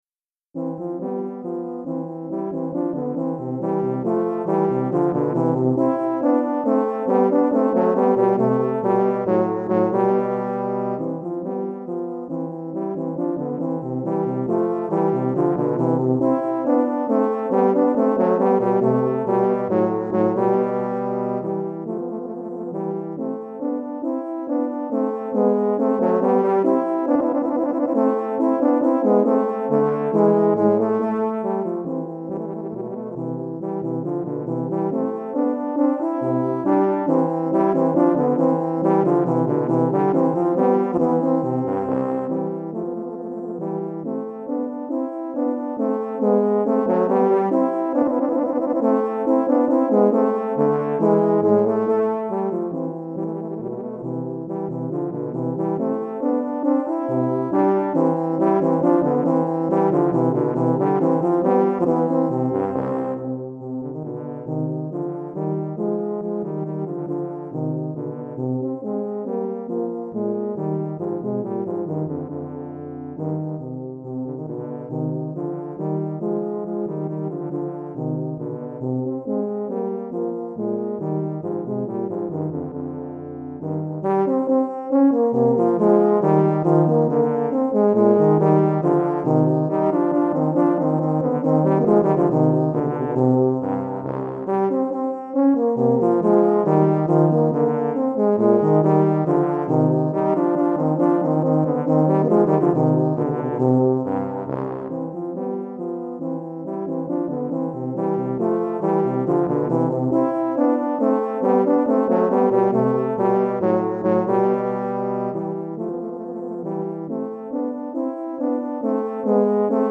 Recueil pour Tuba, euphonium ou saxhorn - 2 Tubas